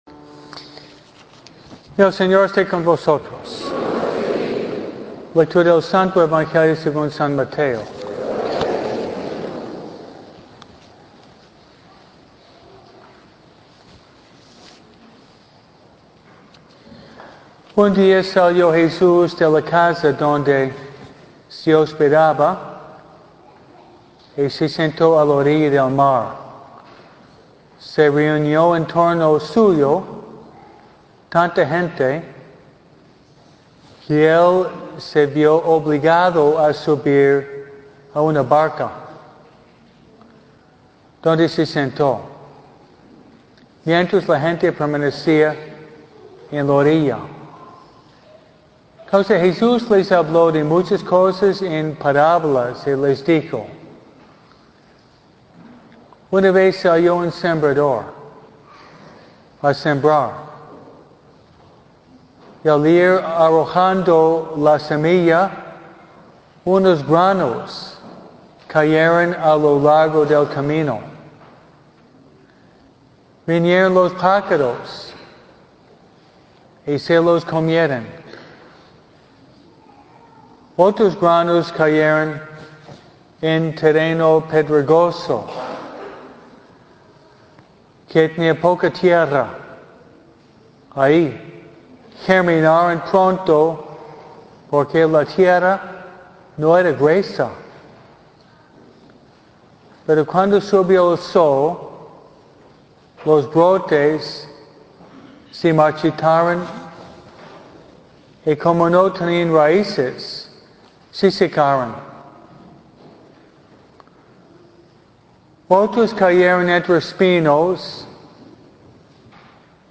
MISA – EL SEMBRADOR II